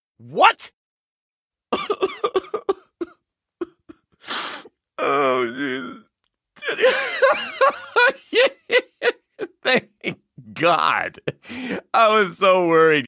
crying-laughter_02